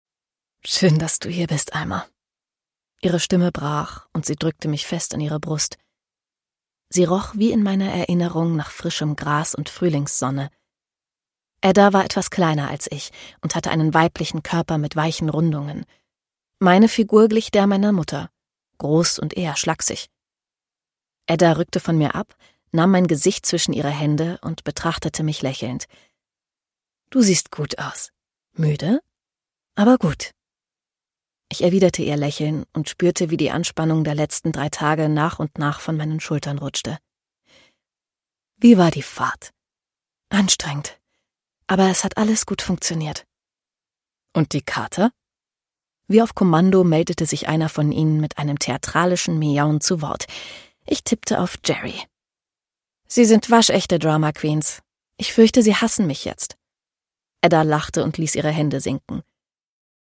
Produkttyp: Hörbuch-Download Gelesen von